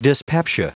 Prononciation du mot dyspepsia en anglais (fichier audio)